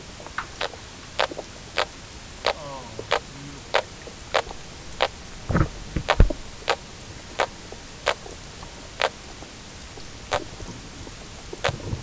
Rough scad spectrogram Spectrogram of Rough scad, Trachurus lathami sounds recorded at the Cotuit Town Pier on 9 September 2013.
Rough scad sound spectrogram zoomed Spectrogram zoomed to a single grunt.